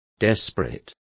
Complete with pronunciation of the translation of desperate.